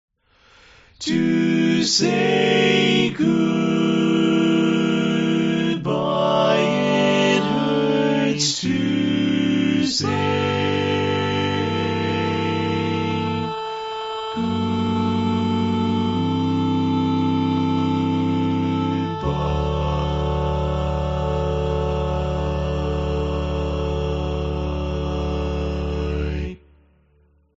Key written in: D Major
How many parts: 4
Type: Barbershop
All Parts mix:
Learning tracks sung by